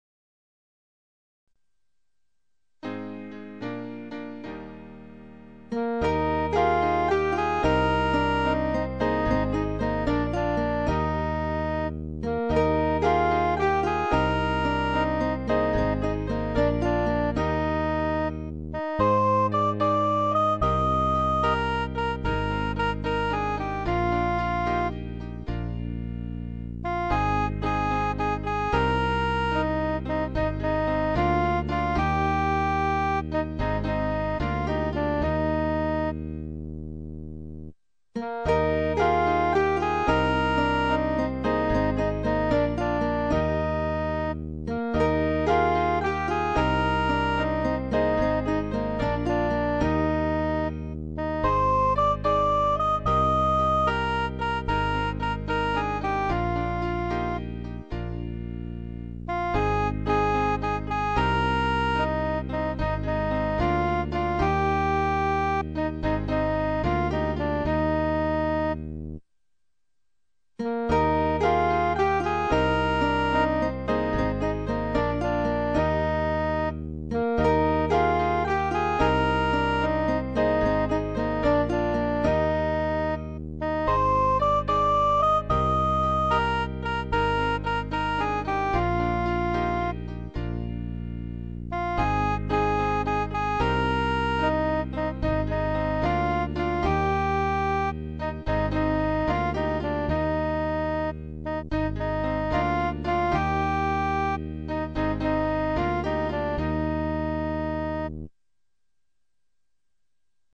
Version instrumentale :